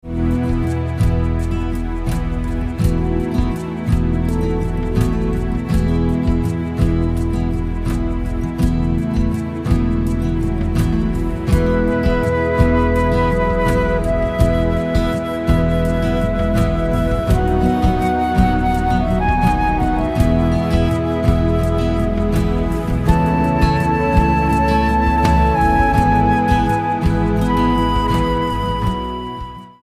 STYLE: Ambient/Meditational